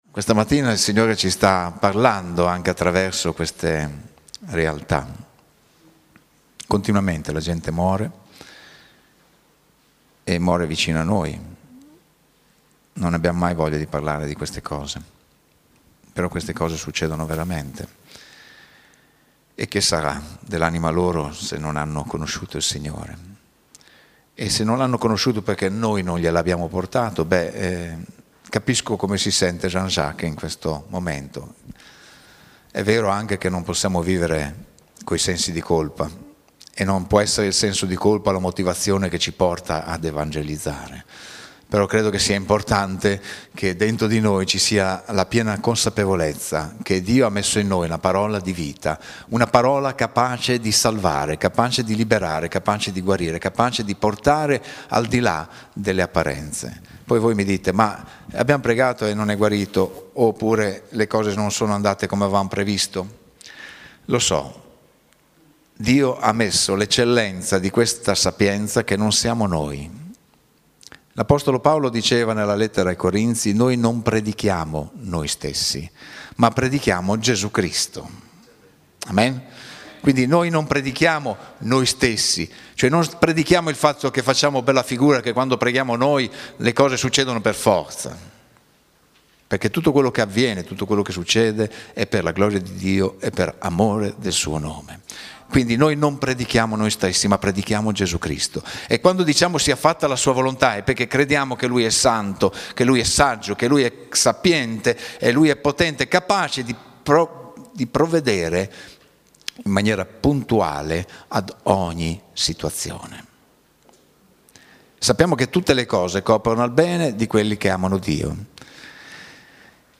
Oggi è stato un tempo di preziose e significative testimonianze. Testimonianze di sofferenza, ma anche di grazia e speranza.